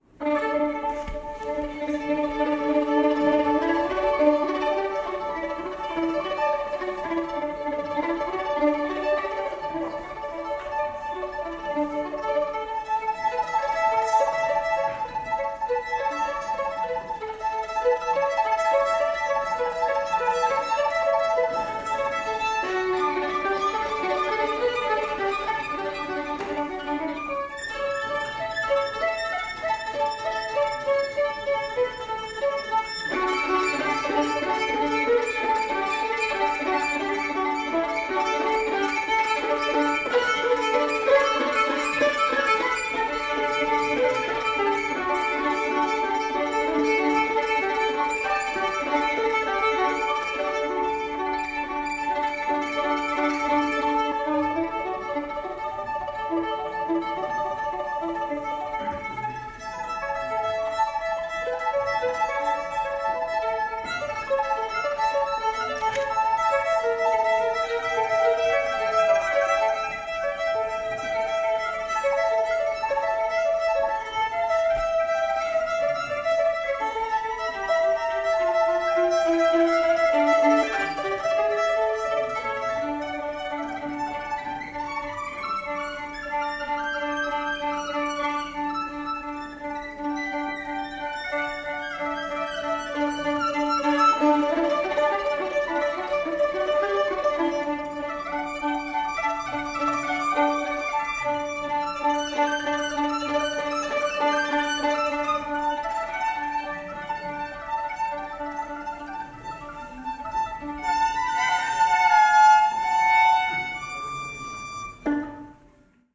Playing the Locatelli ‘Laberinto…’ to Frank Auerbach’s’Summer Morning’ (1991) Tate Brita n10 3 16
LO FI mobile phone recording